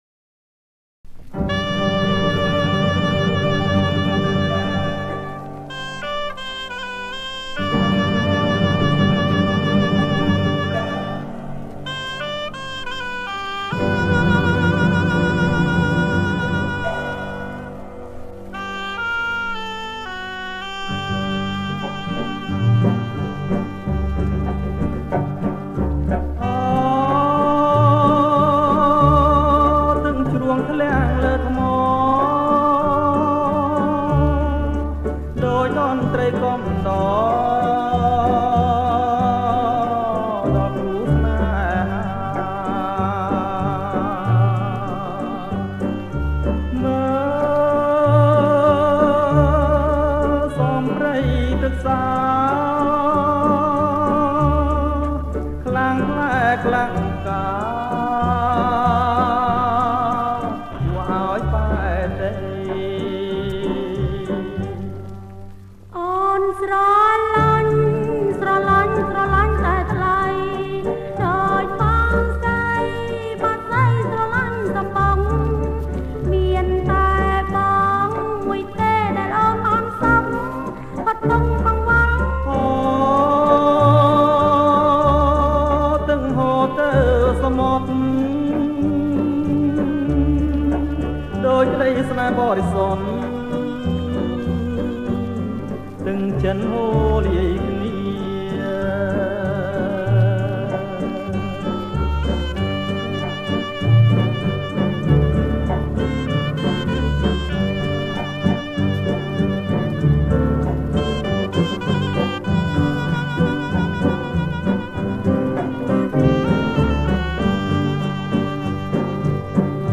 • ប្រគំជាចង្វាក់ Bolero Lent